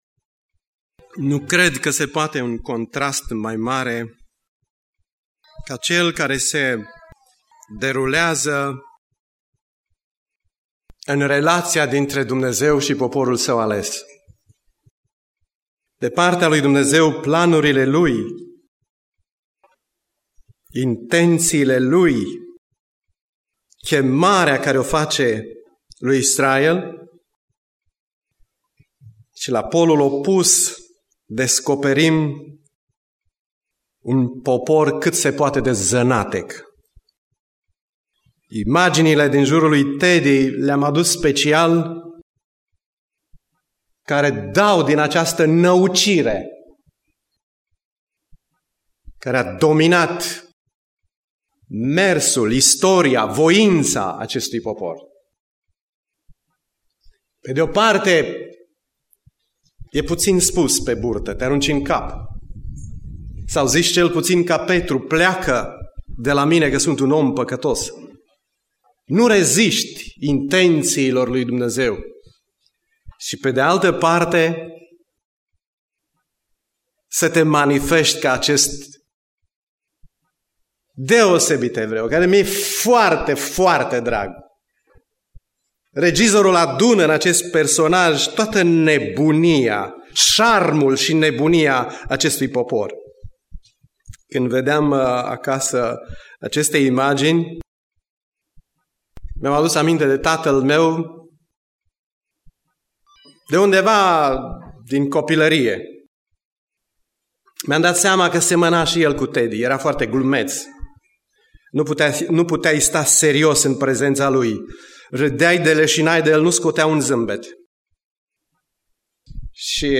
Predica Aplicatie - Ieremia 31b